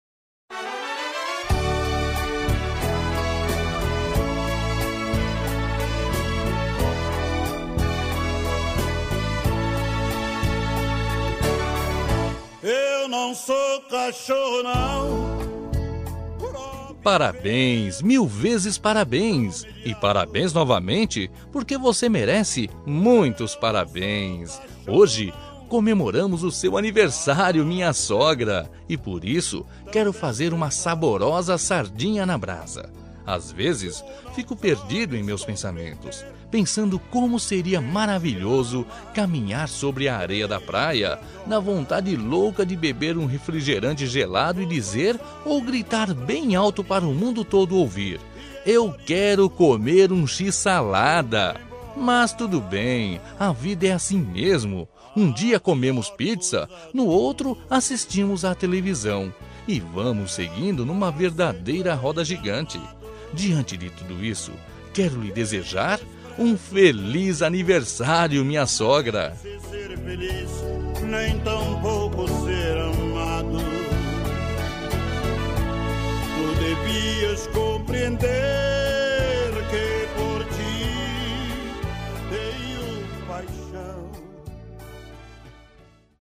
Aniversário de Humor – Voz Masculina – Cód: 200216